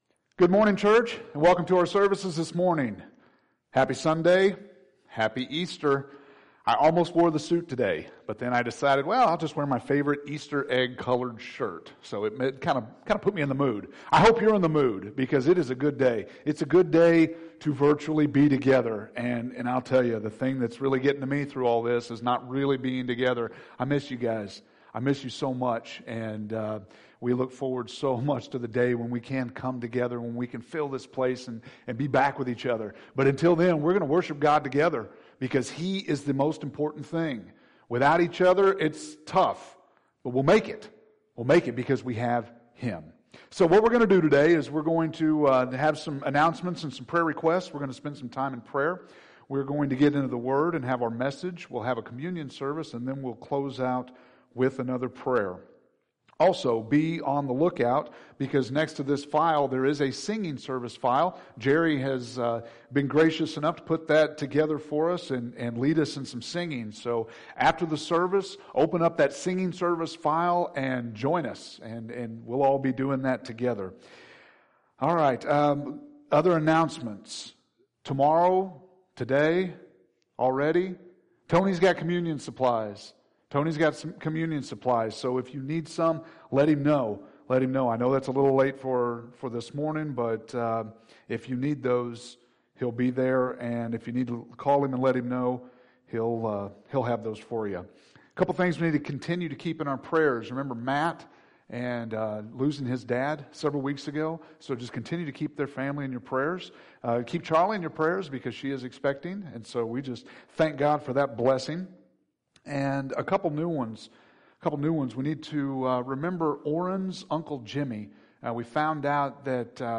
April 12th – Sermons